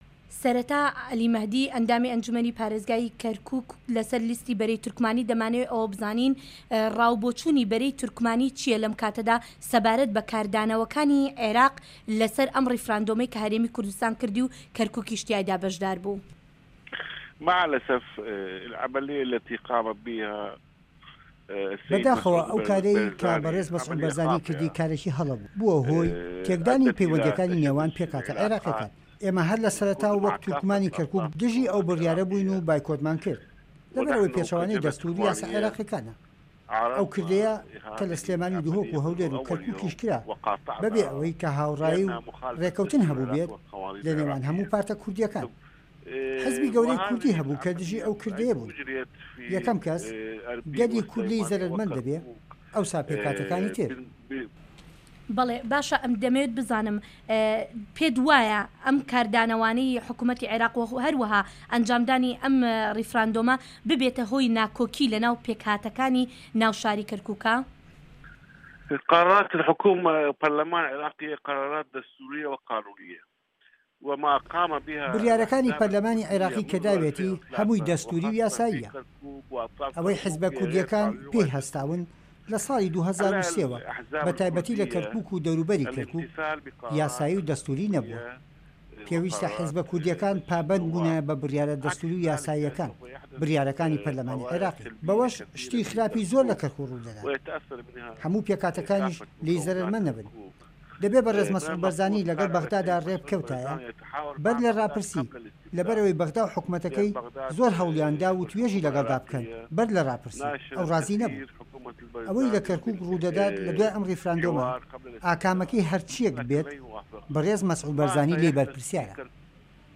وتووێژ لەگەڵ عەلی مەهدی